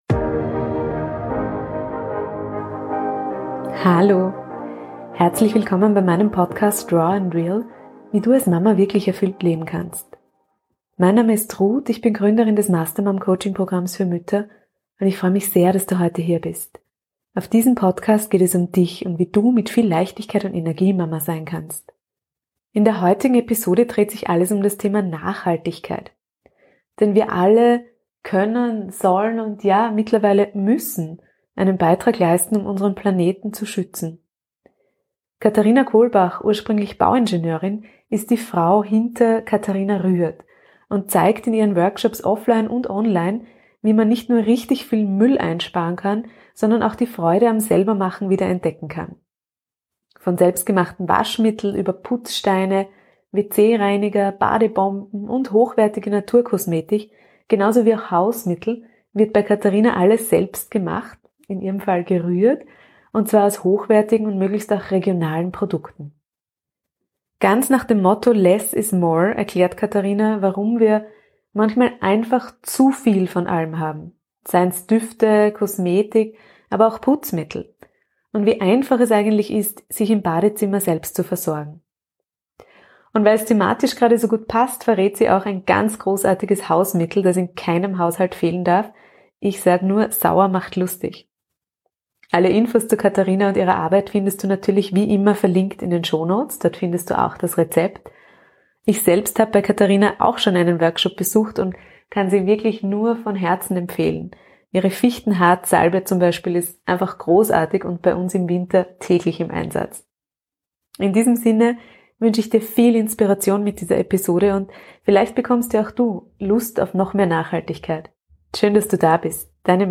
#85 Über Zero Waste, Naturkosmetik und ein nachhaltiges Leben. Interview